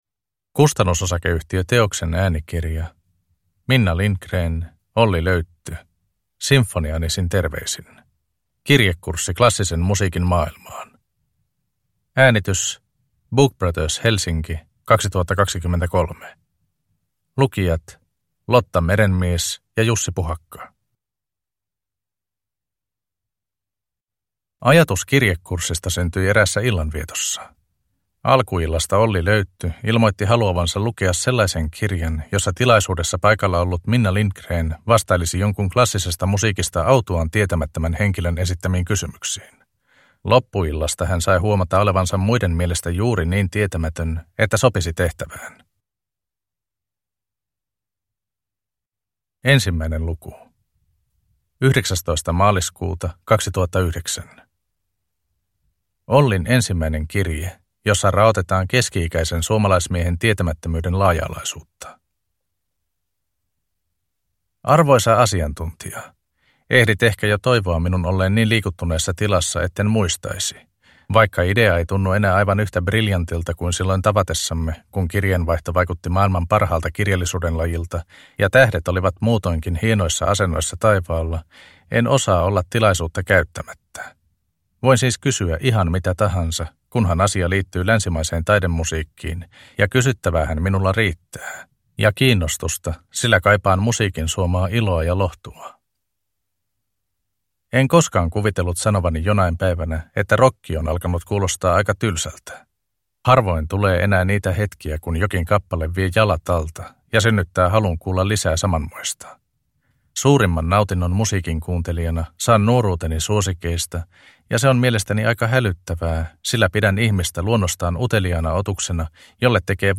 Sinfoniaanisin terveisin – Ljudbok – Laddas ner